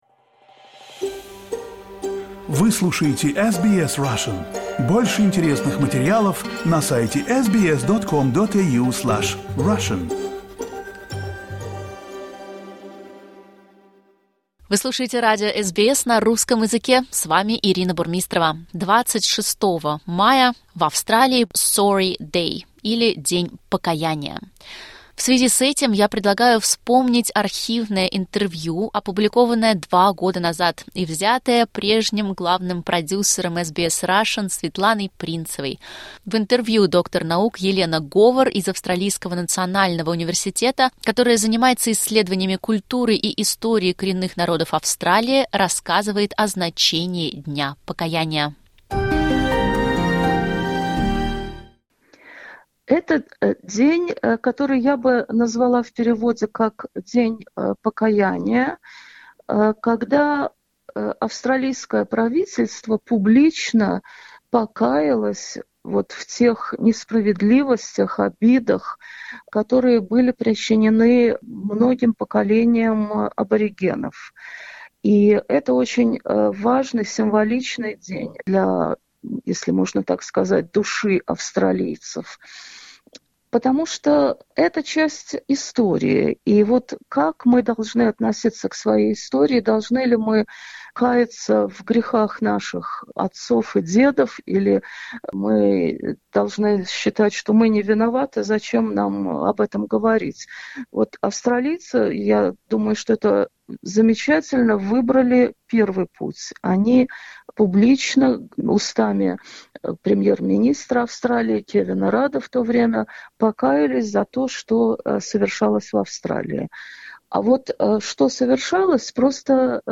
Интервью было впервые опубликовано в 2022 году.